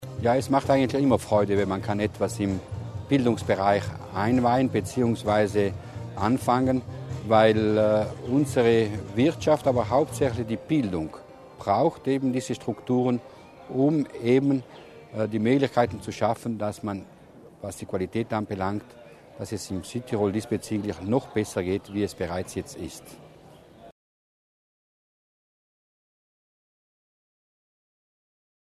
Landeshauptmann Durnwalder zum Ausbau der Schule
LPA - Für den vierstöckigen Zubau der Landesberufschule für Gast- und Nahrungsmittelgewerbe "Emma Hellenstainer“ in Brixen haben Landeshauptmann Luis Durnwalder, Bautenlandsrat Florian Mussner und Bildungslandesrätin Sabina Kasslatter Mur heute, 12. Mai, symbolisch den Grundstein gelegt.